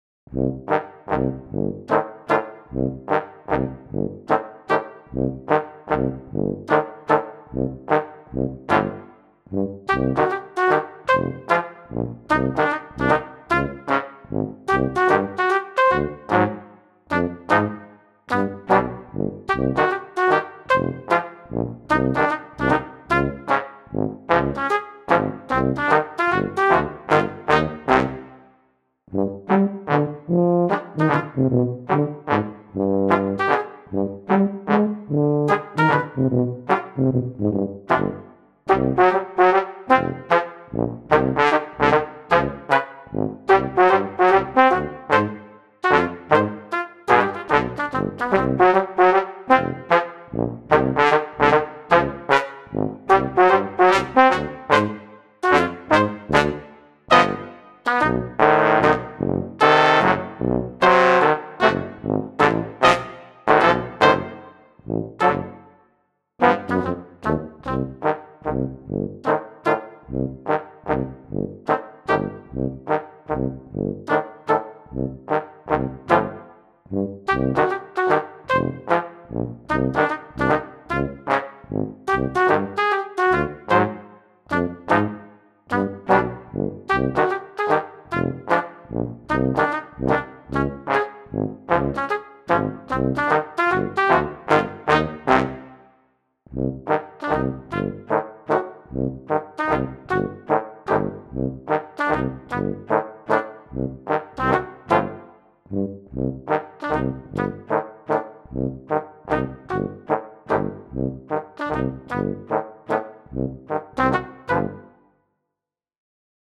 Brass quartet - The shadows (Classical miniature)